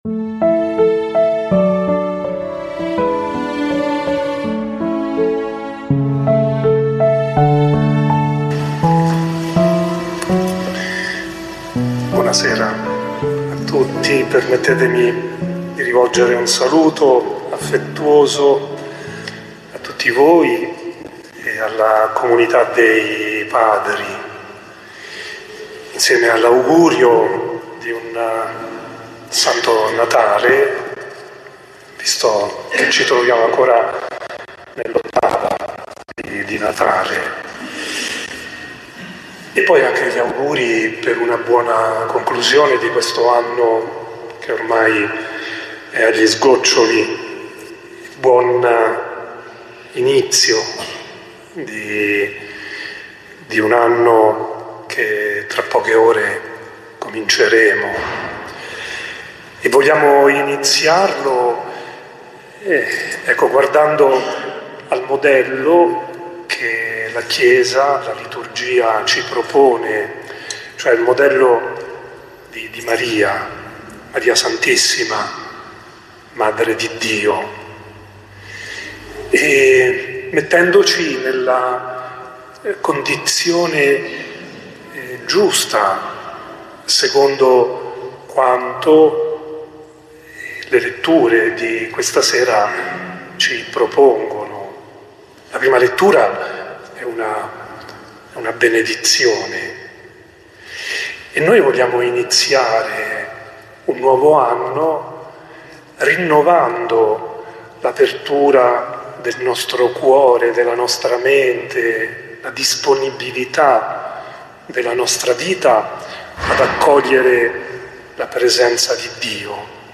Omelia dell’Arcivescovo in occasione della celebrazione di ringraziamento presso la Parrocchia del Rosario a San Ferdinando
Omelie
Arcivescovo-Omelia-celebrazione-di-ringraziamento-presso-la-Parrocchia-del-Rosario-a-San-Ferdinando.mp3